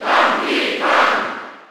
File:Donkey Kong Cheer German SSB4.ogg
Donkey_Kong_Cheer_German_SSB4.ogg.mp3